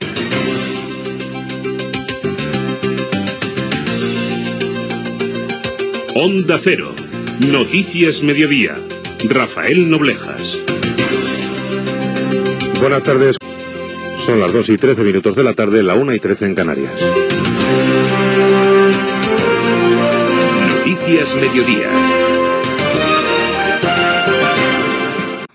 Careta del programa, hora i salutació inicial
Informatiu